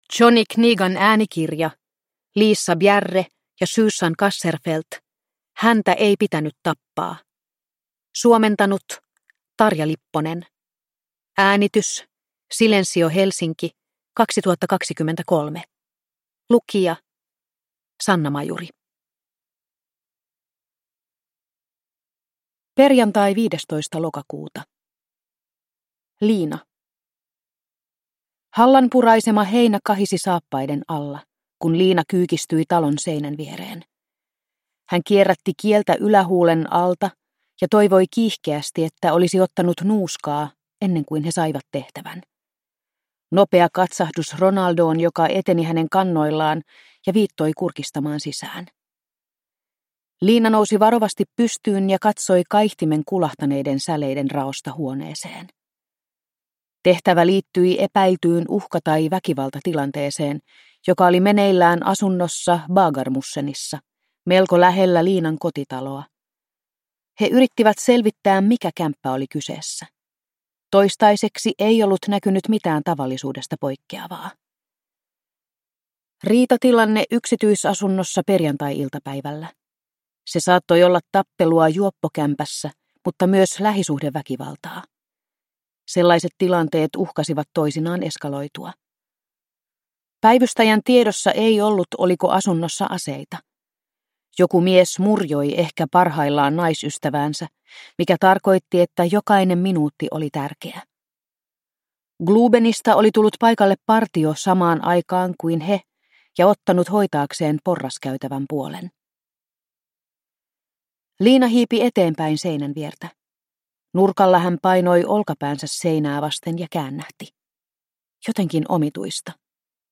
Häntä ei pitänyt tappaa – Ljudbok – Laddas ner